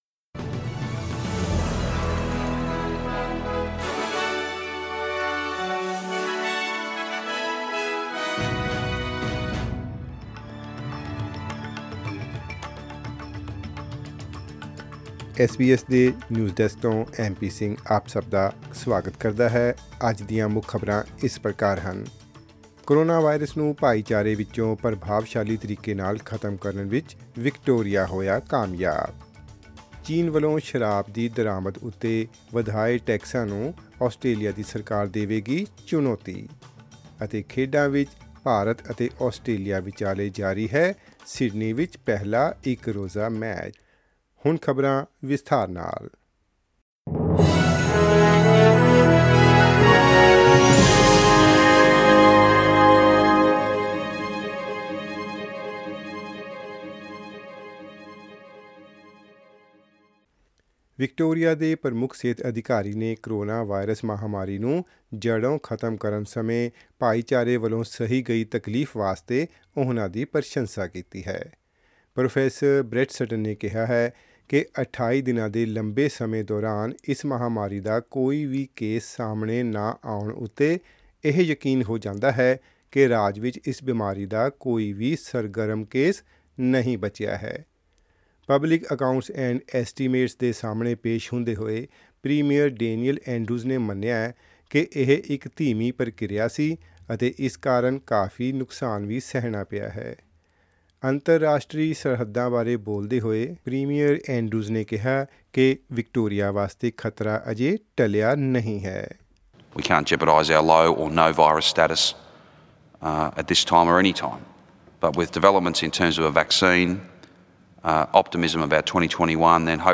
Click on the player at the top of the page to listen to the news bulletin in Punjabi.